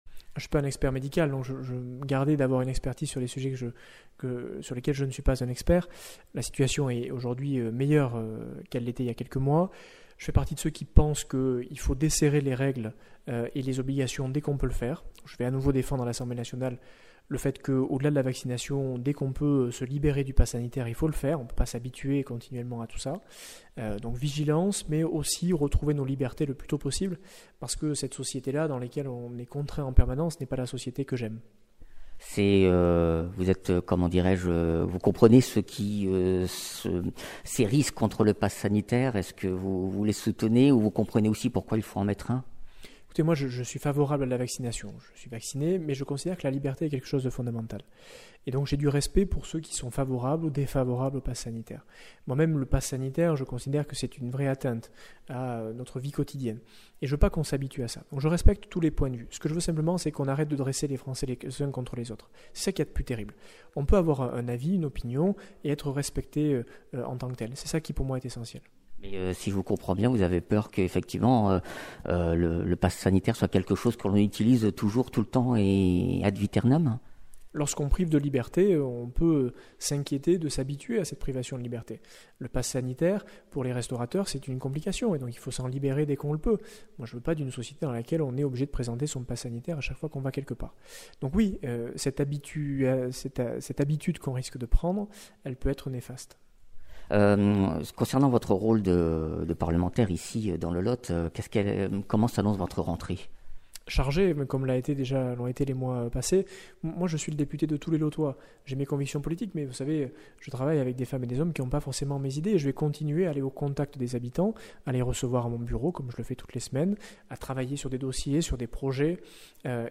Interviews
Invité(s) : Aurélien Pradié, député LR du Lot et secrétaire général LR